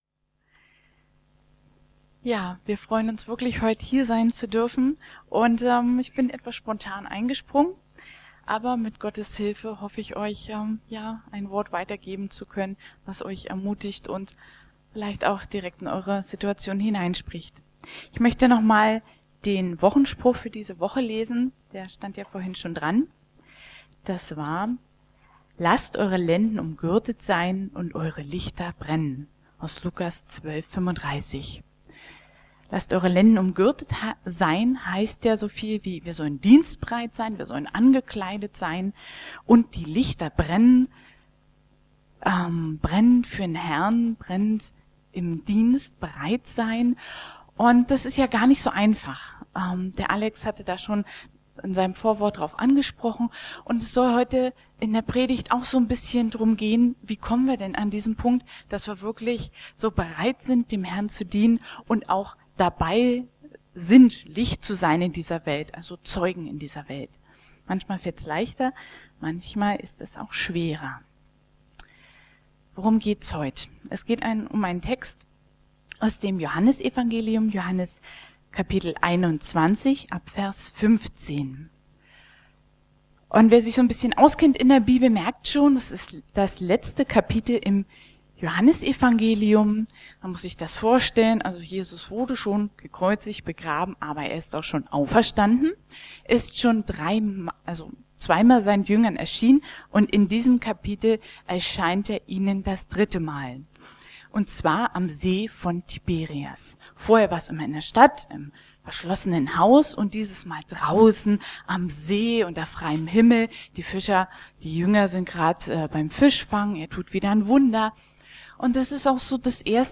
Liebst du mich? | Marburger Predigten